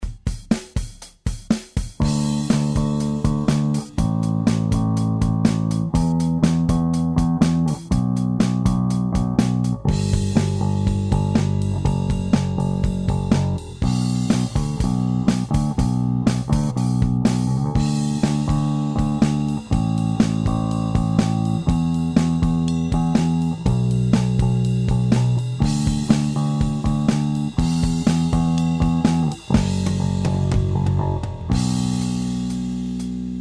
Вложения Bass_DrumProcessed.mp3 Bass_DrumProcessed.mp3 522,2 KB · Просмотры: 204